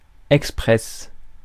Ääntäminen
IPA: /ɛk.spʁɛs/